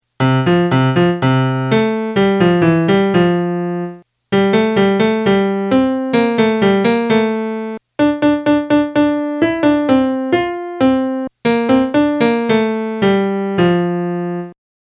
dove-te-vett-melody.mp3